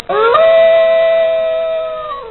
wolf2_KeTmuQp.mp3